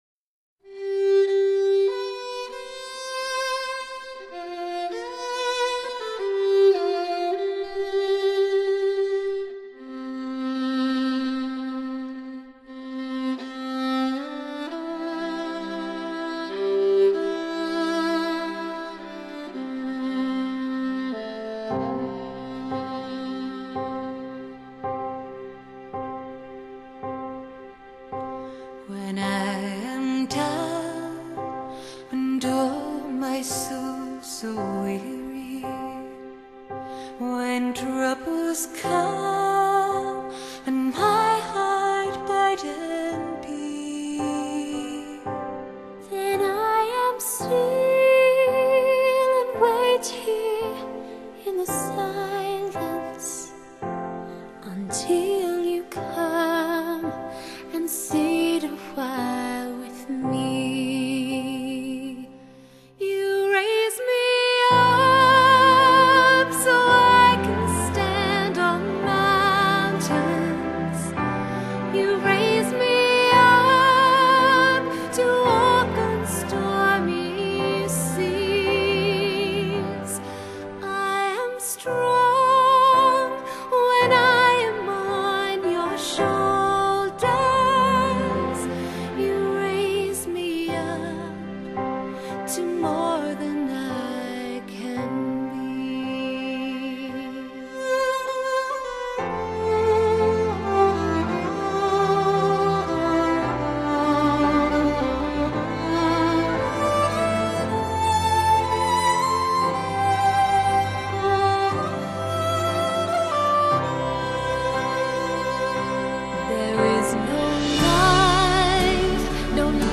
【2008全新兒童唱詩班合音版本】